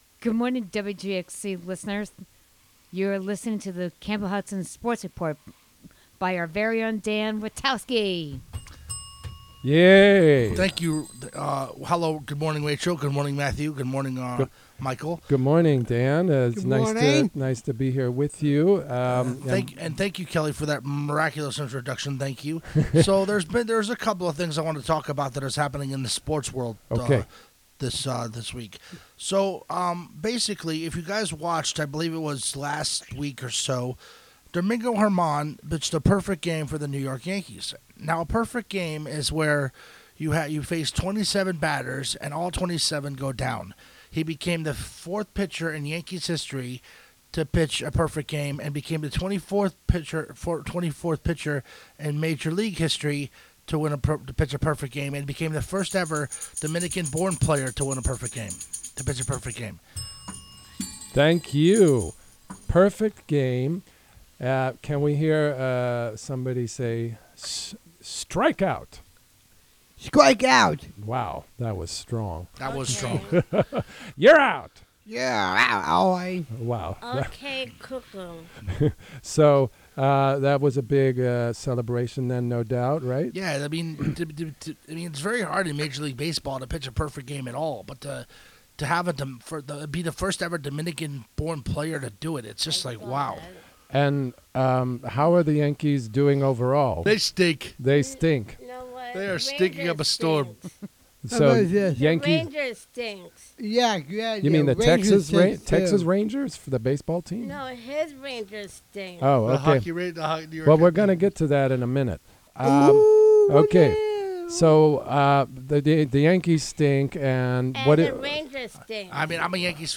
Sports report